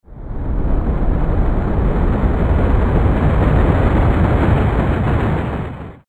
Heavy Rock Movement Sound Effect
Shifting a large rock or stone on a rough surface. Rock sliding on stone. Massive movement caused by an earthquake or other natural disaster.
Heavy-rock-movement-sound-effect.mp3